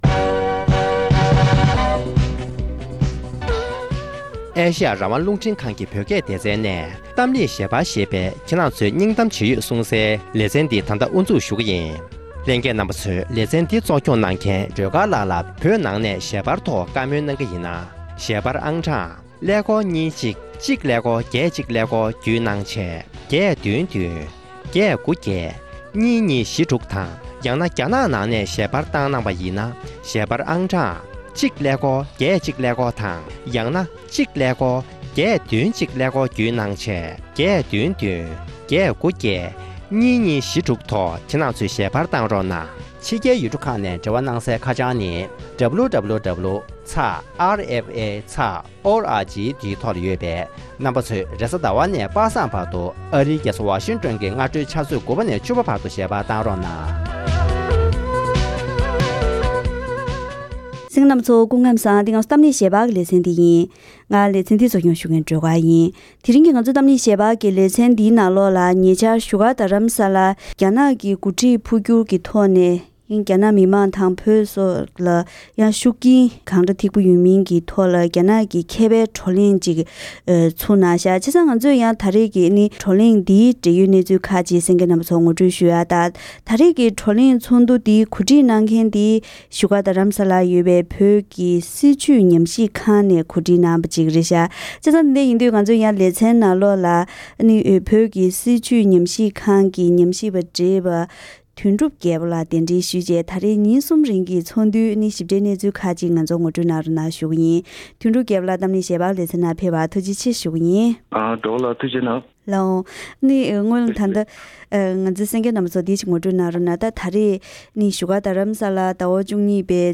༄༅། །དེ་རིང་གི་གཏམ་གླེང་ཞལ་པར་ལེ་ཚན་ནང་། བོད་ཀྱི་སྲིད་བྱུས་ཉམས་ཞིབ་ཁང་གིས་གོ་སྒྲིག་ཐོག ཉེ་ཆར་བཞུགས་སྒར་རྡ་རམ་ས་ལར་སྐོང་ཚོགས་གནང་བའི་རྒྱ་ནག་གི་དཔོན་རིགས་འཕོ་འགྱུར་ཁྲོད་ཀྱི་ཤུགས་རྐྱེན་སྐོར་གྱི་བགྲོ་གླེང་ཚོགས་འདུ་དང་འབྲེལ་ཡོད་གནས་ཚུལ་ཁག་གི་ཐོག་བཀའ་མོལ་ཞུས་པ་ཞིག་གསན་རོགས་གནང་།།